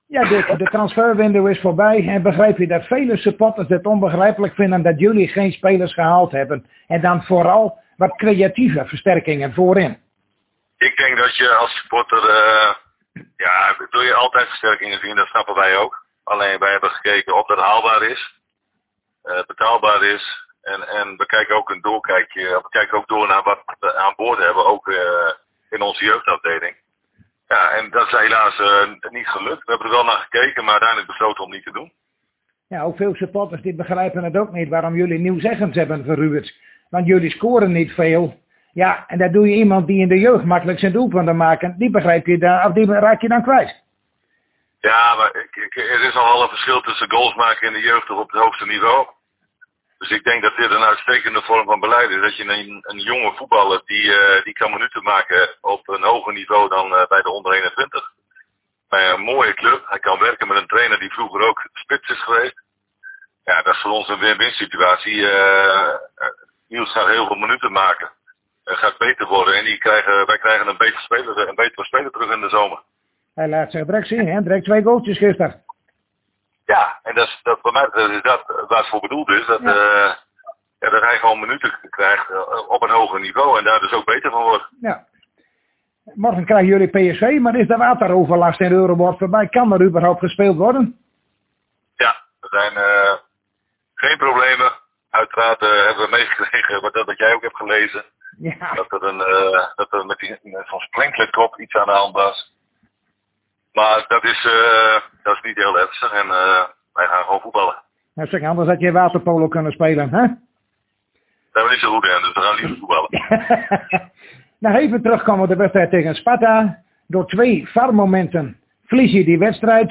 Hierbij het gesprek wat wij zojuist hadden met trainer Dick Lukkien en we babbelden over meerde zaken.
Dick-Lukkien-over-FC-Groningen-PSV-12390.mp3